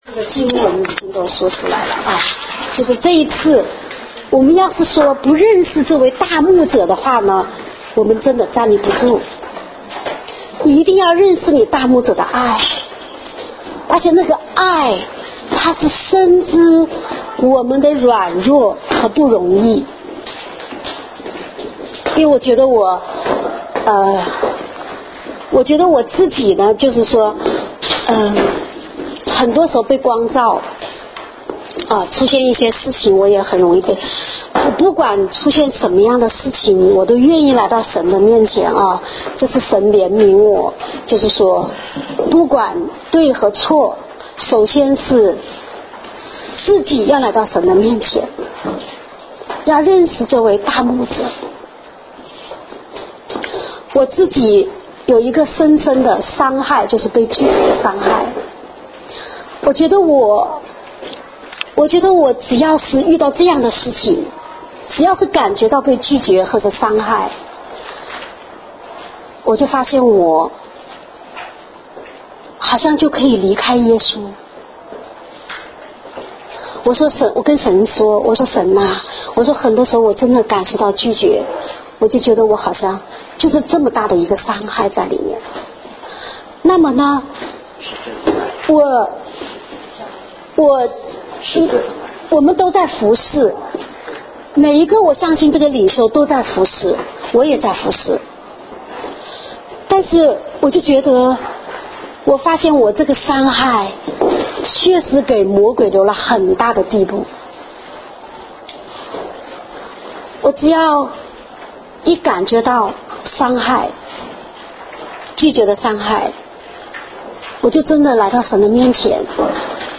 正在播放：--主日恩膏聚会录音（2014-09-07）